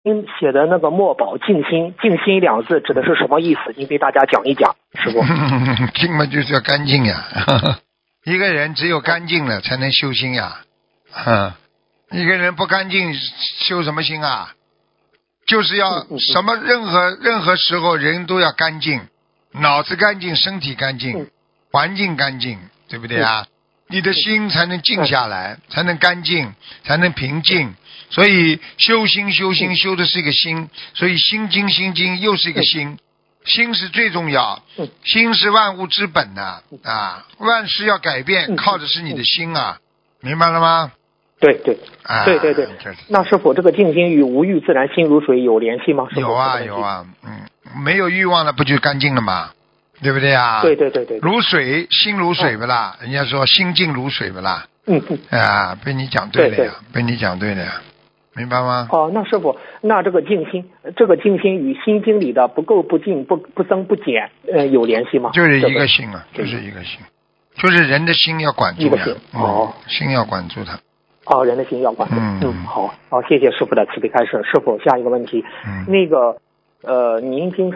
Tanya Jawab